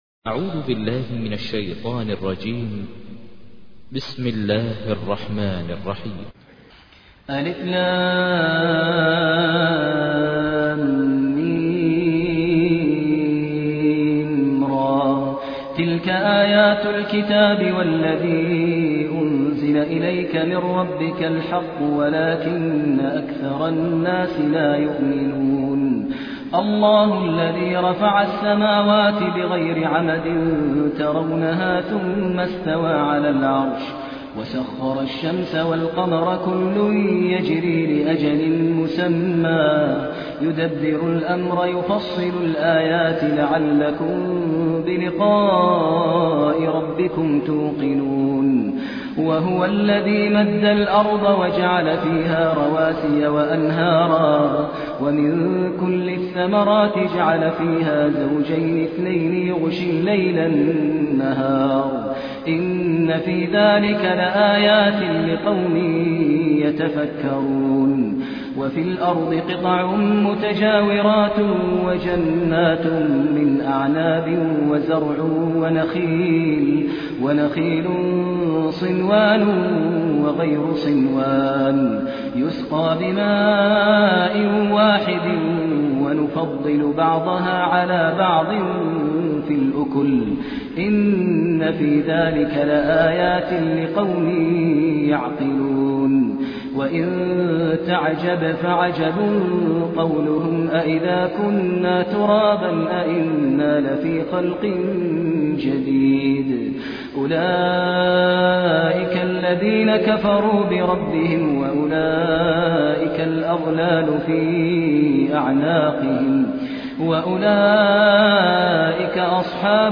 تحميل : 13. سورة الرعد / القارئ ماهر المعيقلي / القرآن الكريم / موقع يا حسين